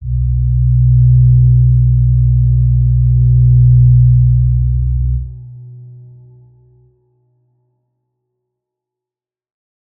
G_Crystal-B2-mf.wav